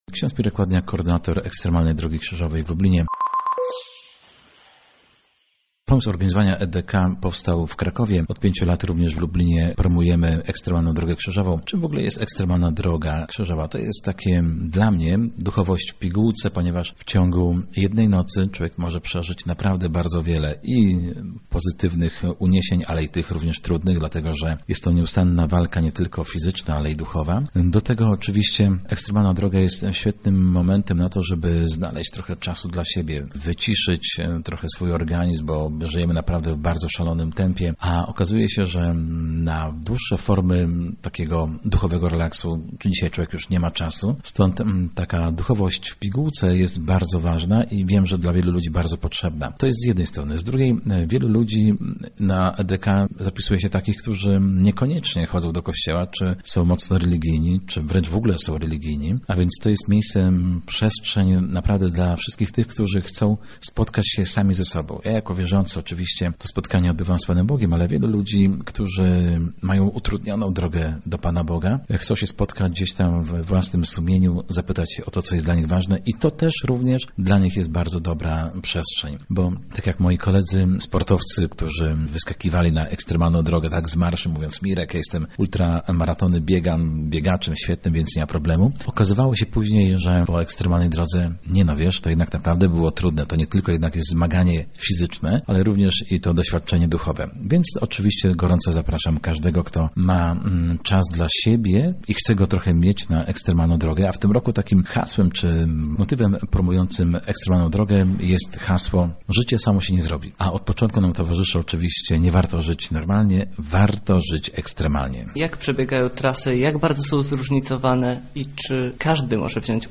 Więcej na ten temat mówi jeden z organizatorów Ekstremalnej Drogi Krzyżowej: